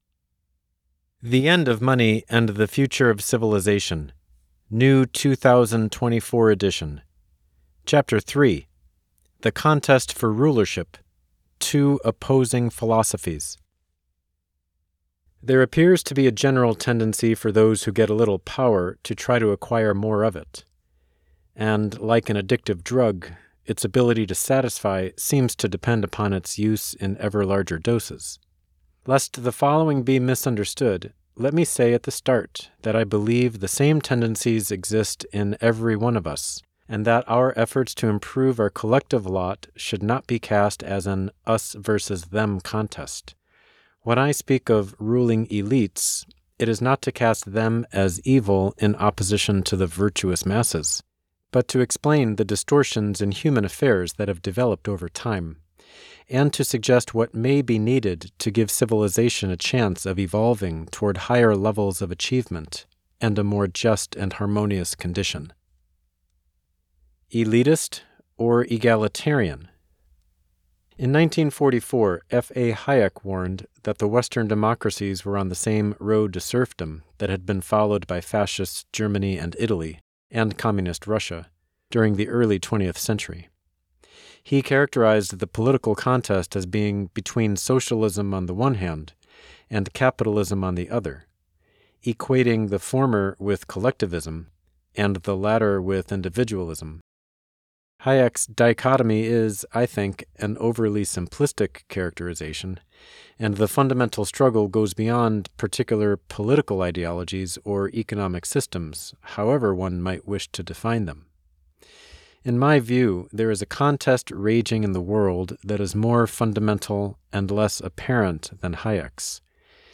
Chapter 3 — Audio narration